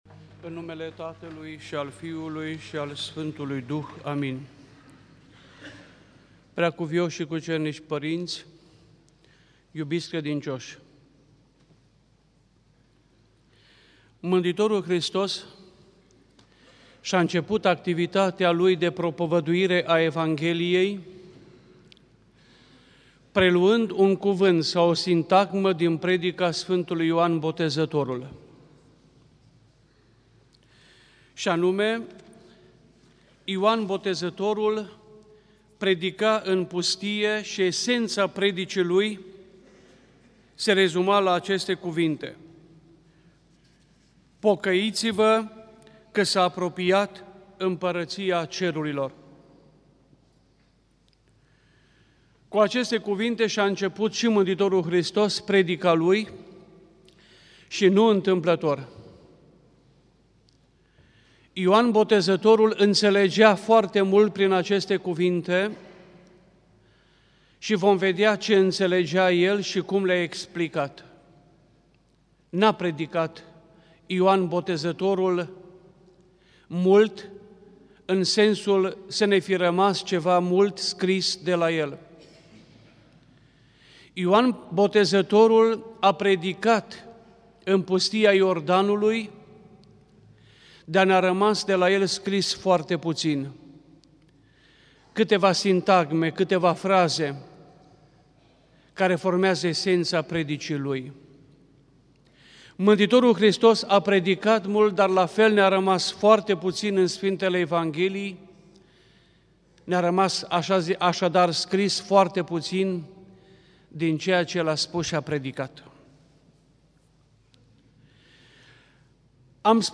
Predică la Duminica după Botezul Domnului